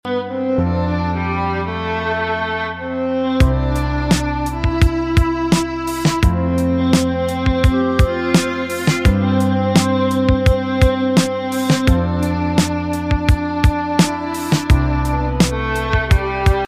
Kumpulan Funny Sound (1) Sound Effects Free Download